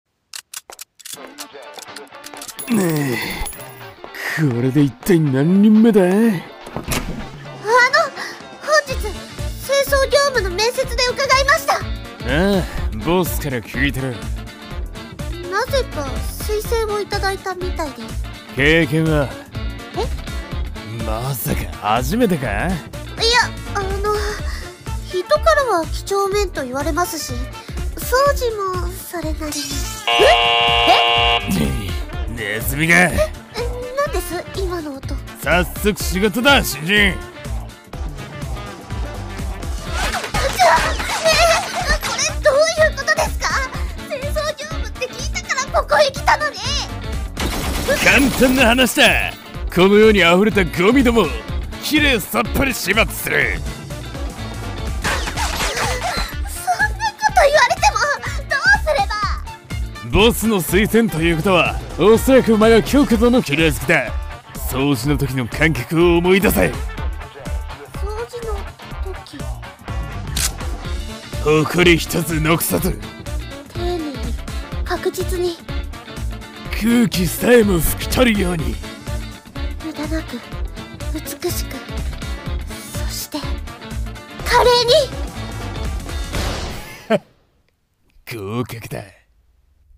【声劇】Vacant